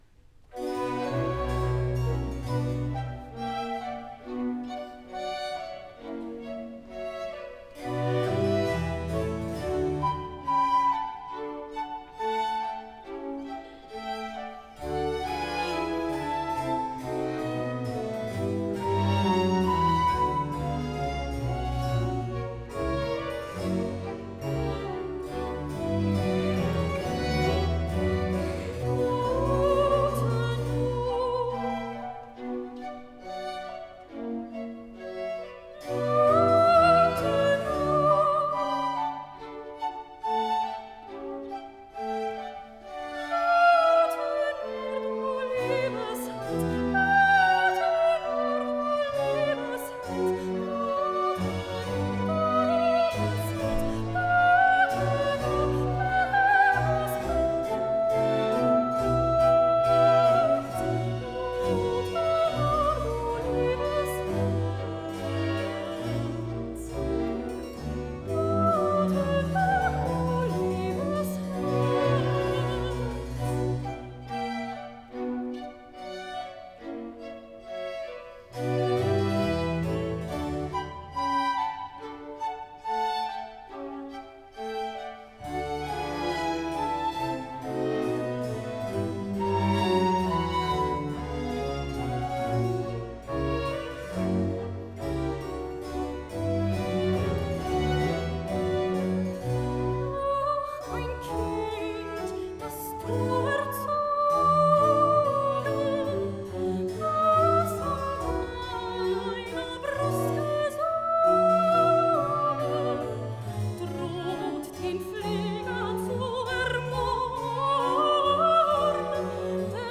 enregistrement haute définition en « live »